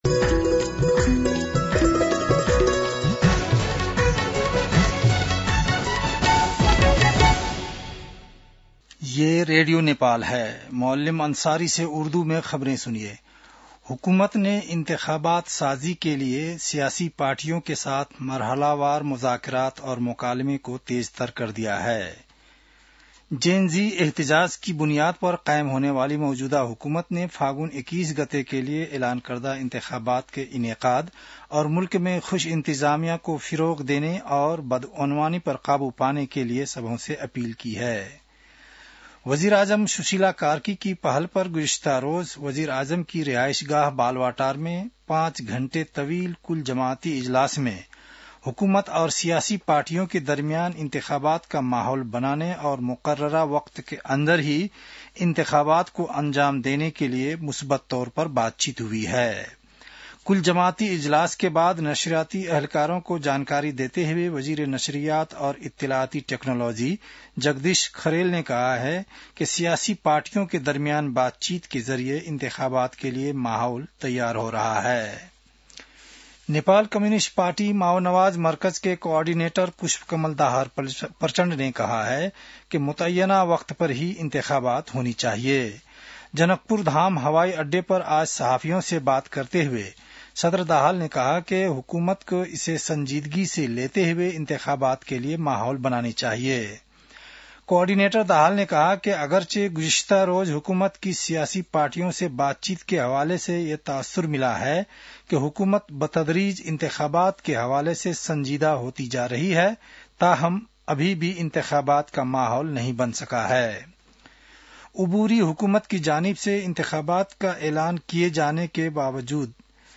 उर्दु भाषामा समाचार : ५ कार्तिक , २०८२